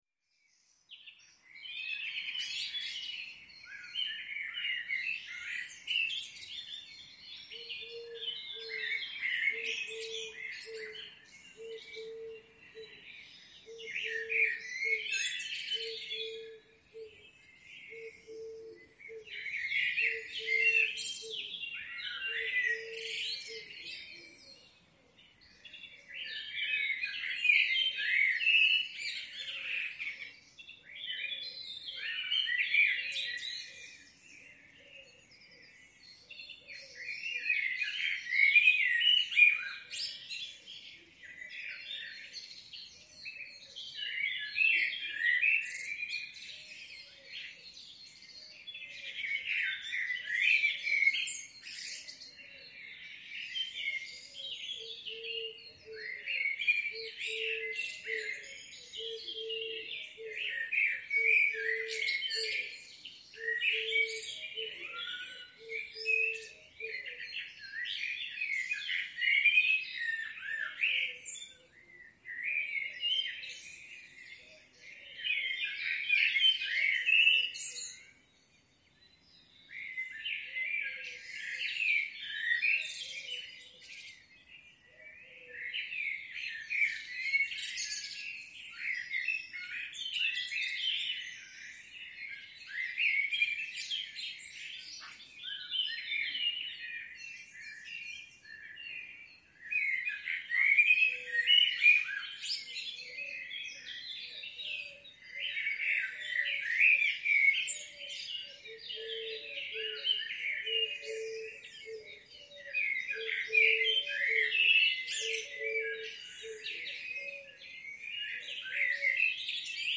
Morning chirping in Carpi